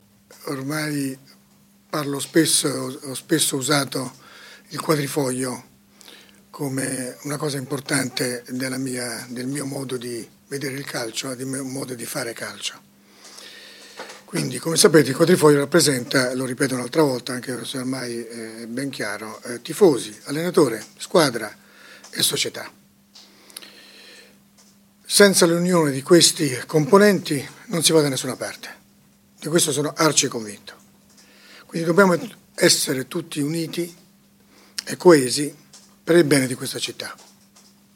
Di seguito gli audio della conferenza stampa